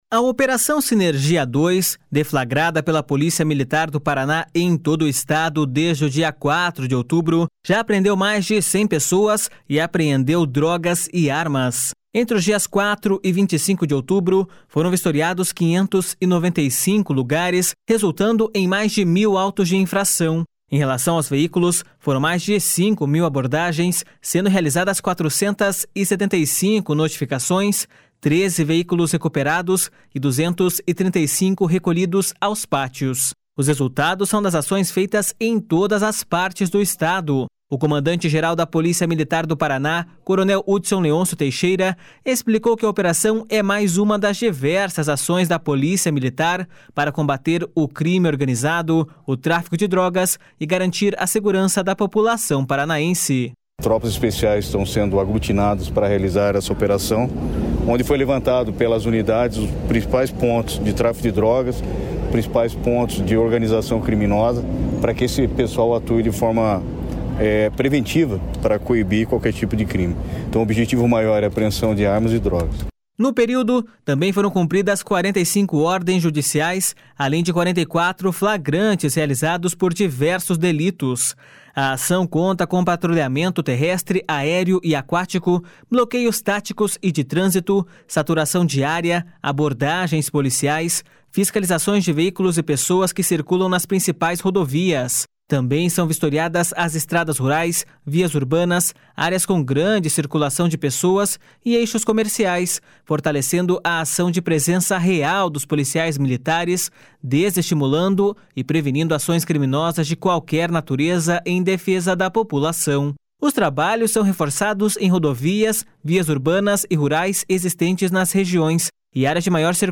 O comandante-geral da Polícia Militar do Paraná, coronel Hudson Leôncio Teixeira, explicou que a operação é mais uma das diversas ações da Polícia Militar para combater o crime organizado, o tráfico de drogas e garantir a segurança da população paranaense.// SONORA HUDSON LEÔNCIO TEIXEIRA.//